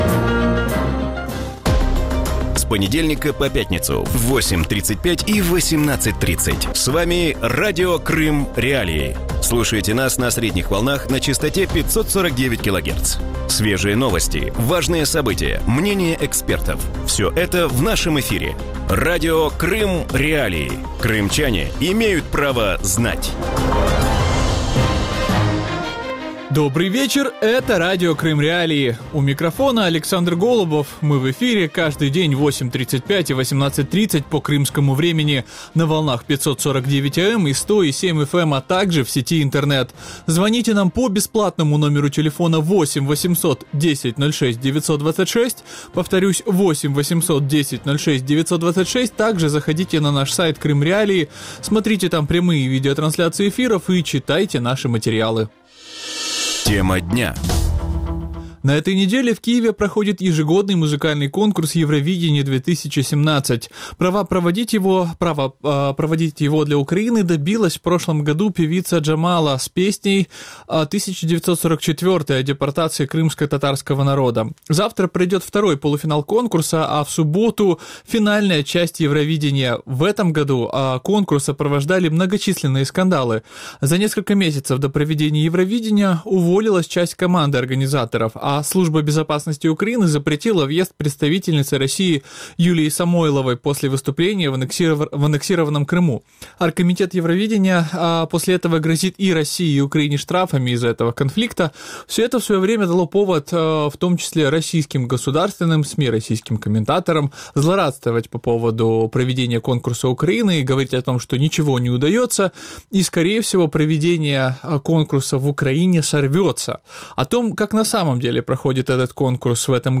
В вечернем эфире Радио Крым.Реалии обсуждают «Евровидение-2017» в Киеве. Как проходит песенный конкурс? Удастся ли Украине заработать на проведении конкурса?